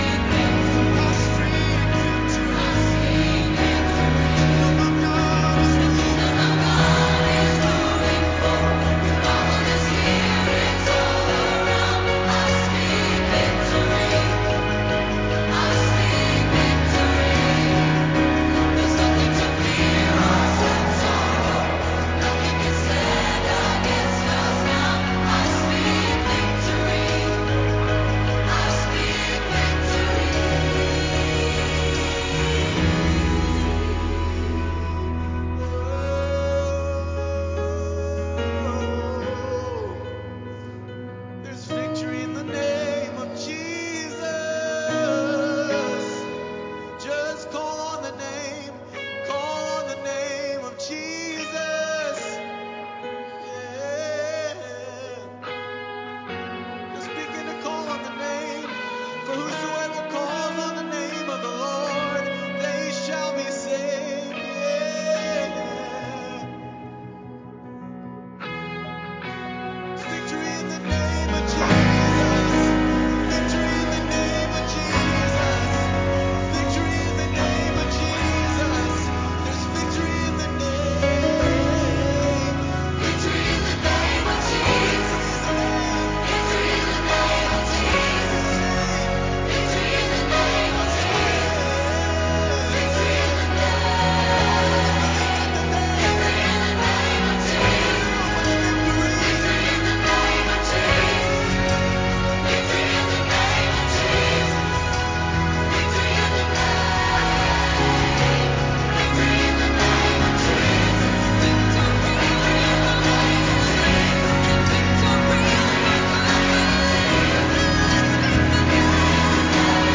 9-3-Sunday-morning-worship-CD.mp3